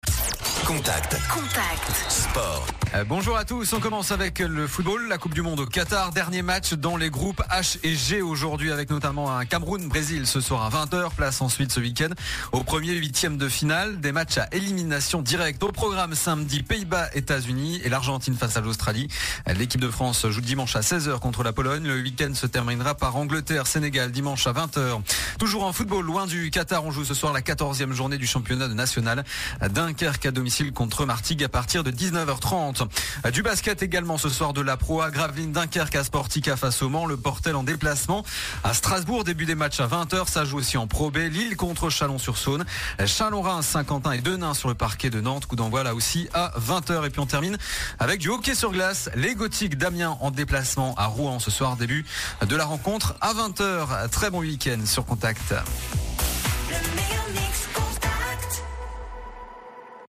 Le journal des sports du vendredi 2 décembre